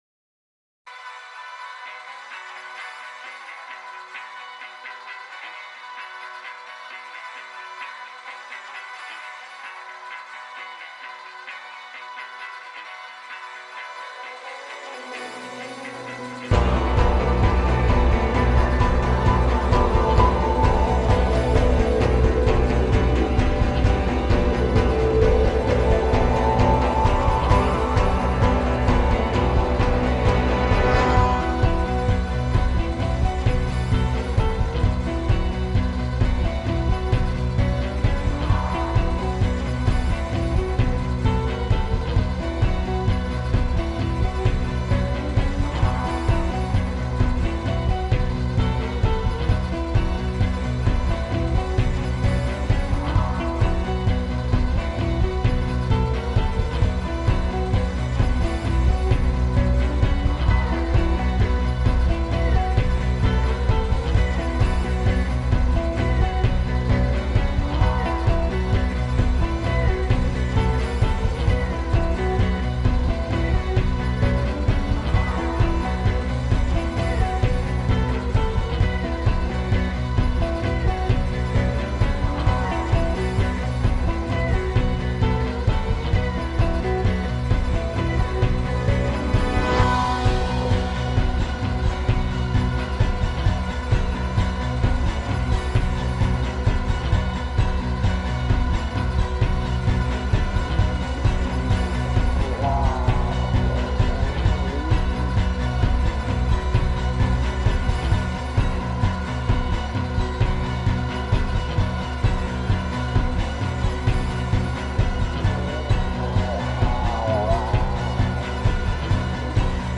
Looping, open-chord guitar figures build and mutate
modern drone minimalism does not need to be ambient